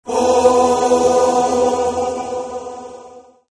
効果音 神々しいMP3
神々しい (着信音無料)